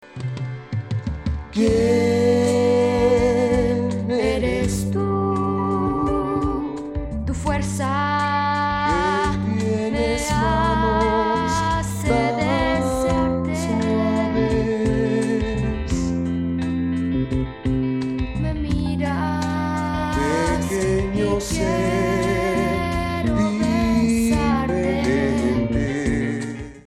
rock opera
guitar
drums
bass